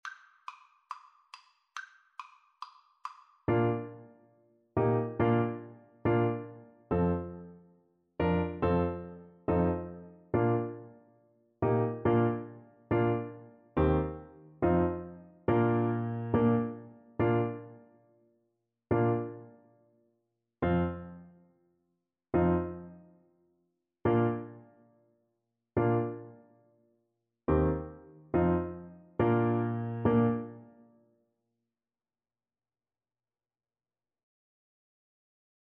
Fast = c. 140